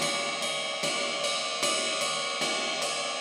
Closed Hats
RIDE_LOOP_12.wav